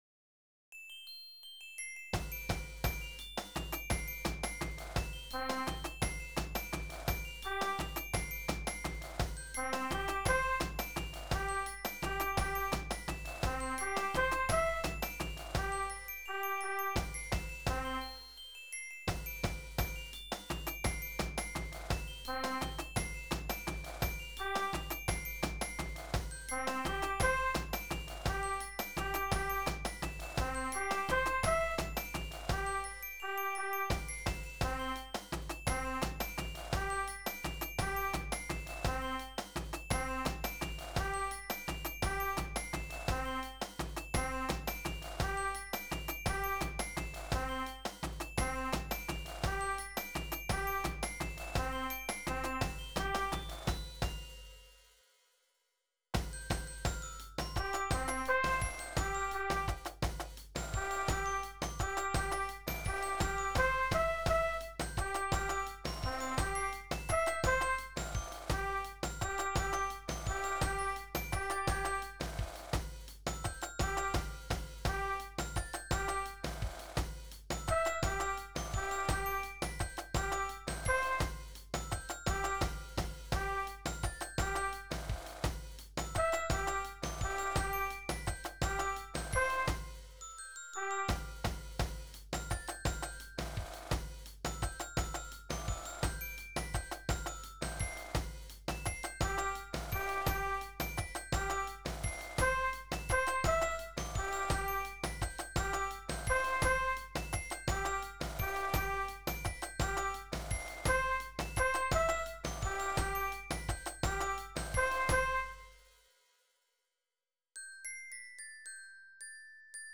Música para banda
Resumen : RESUMEN : Esta cartilla esta hecha para los formatos de banda marcial tradicional (lira, clarín, caja, granadera, tambora, bombo).
AnexoB_Popurri chocoano..wav